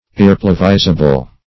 Search Result for " irreplevisable" : The Collaborative International Dictionary of English v.0.48: Irrepleviable \Ir`re*plev"i*a*ble\, Irreplevisable \Ir`re*plev"i*sa*ble\, a. (Law) Not capable of being replevied.
irreplevisable.mp3